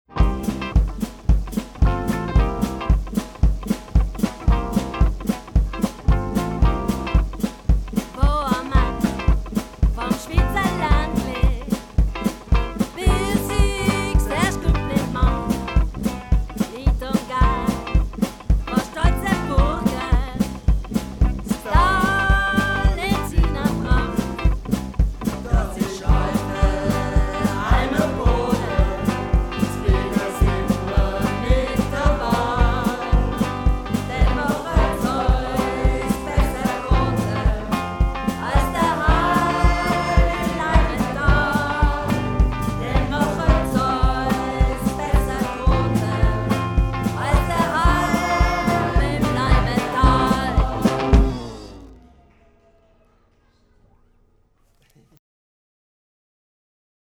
Musikalische Leitung: 2 Alphörner, 1 Schlagzeug, 1 Posaune